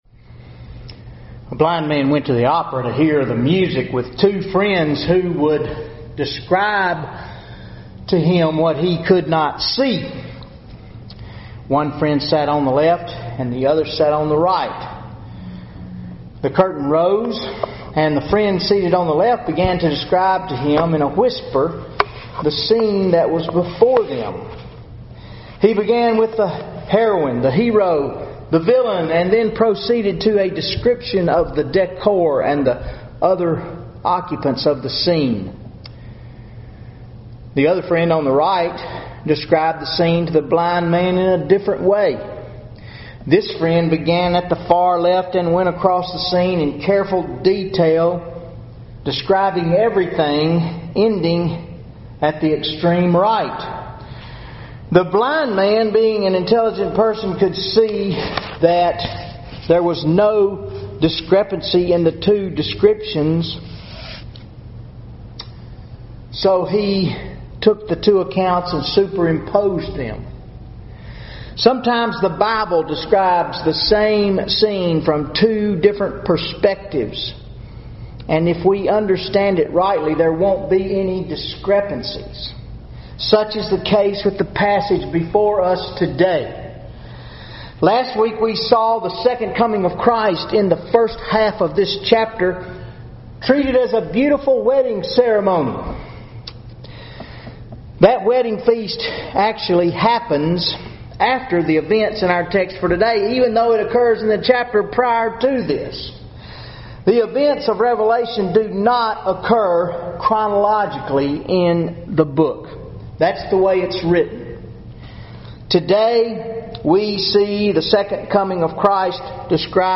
Sunday Sermon 02/15/2015 Revelation 19:11-21 How Does Human History End?